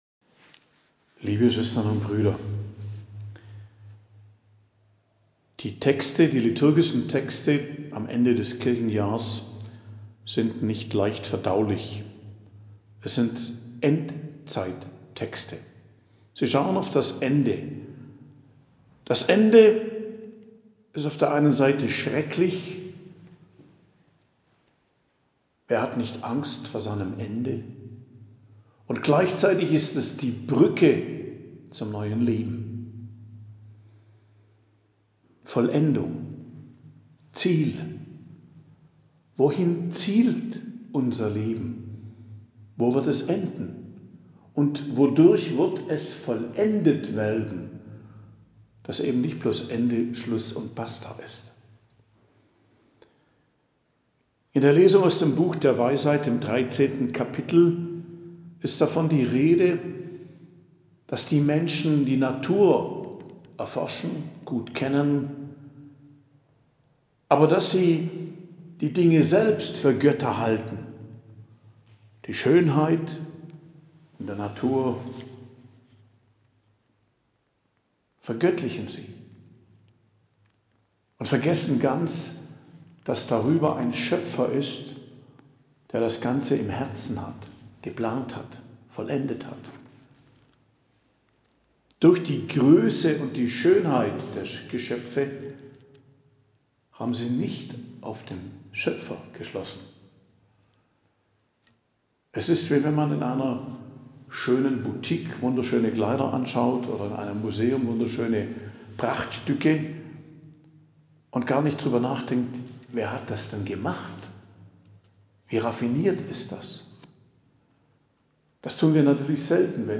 Predigt am Freitag der 32. Woche i.J., 17.11.2023 ~ Geistliches Zentrum Kloster Heiligkreuztal Podcast